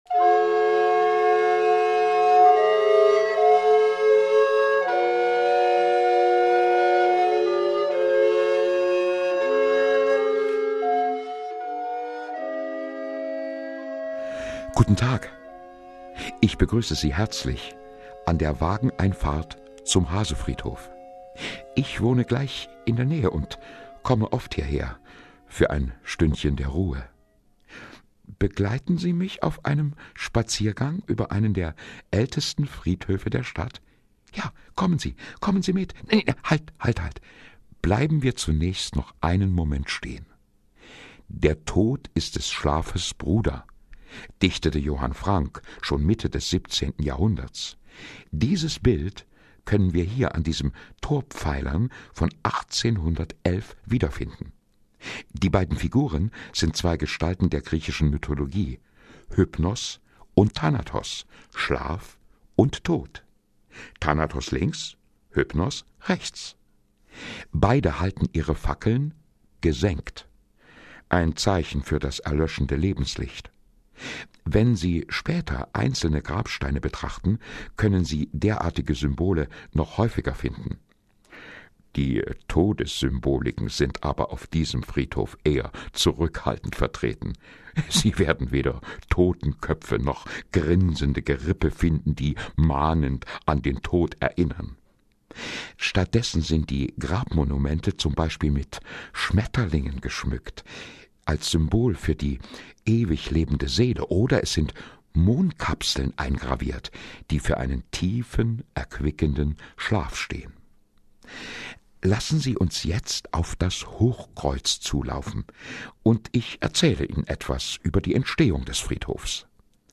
Audioführung über den Hasefriedhof
Zusammen mit den Klängen des Ensemble OLegrettos werden einem die (historischen) Fakten so kurzweilig und spannend vermittelt.